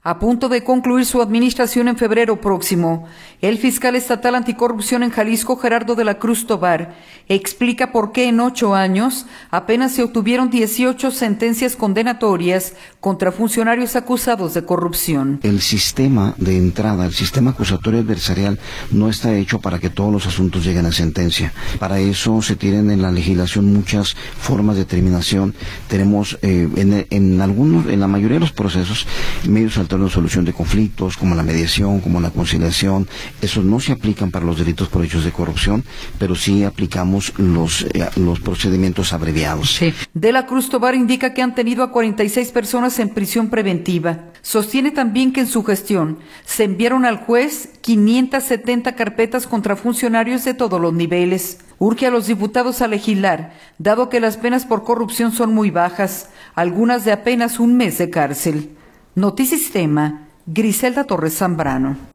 audio A punto de concluir su administración en febrero próximo, el Fiscal Estatal Anticorrupción en Jalisco, Gerardo de la Cruz Tovar, explica por qué en ocho años apenas se obtuvieron 18 sentencias condenatorias contra funcionarios acusados de corrupción.